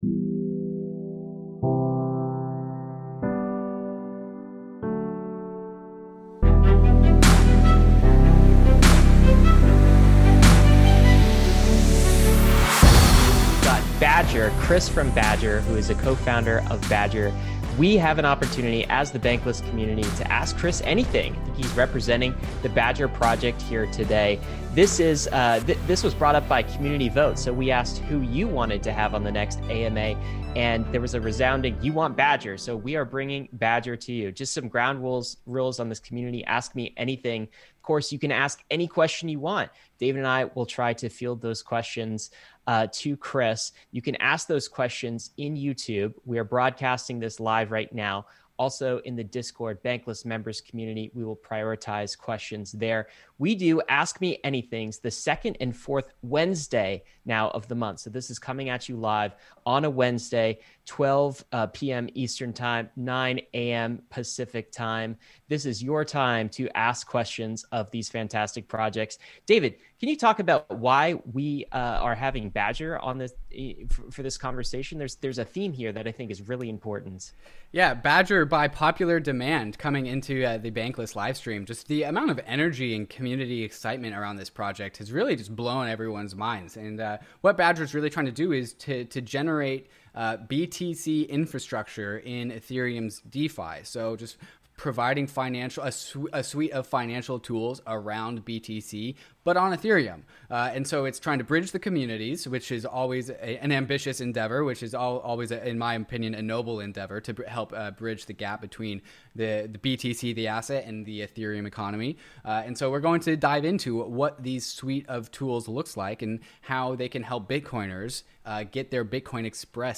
Like all our AMAs, the interview is live and incorporates questions from our Inner Circle Discord and live YouTube Chat.